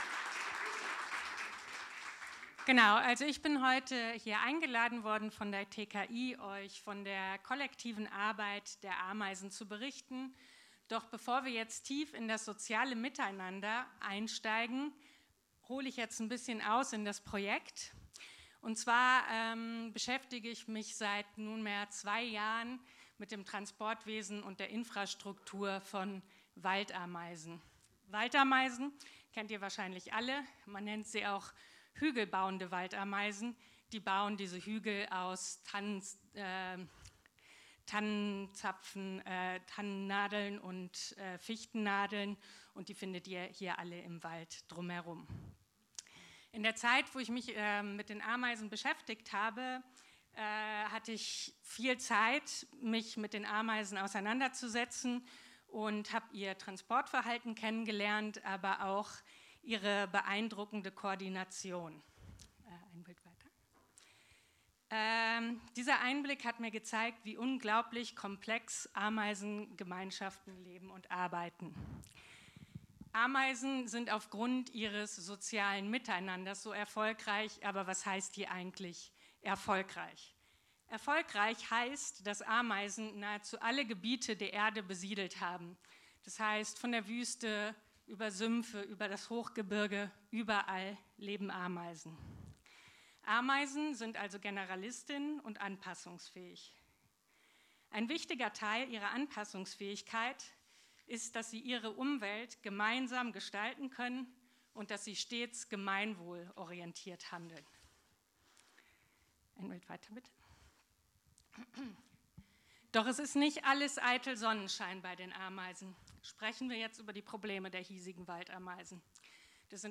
Laudatio – Das Ameisenkollektiv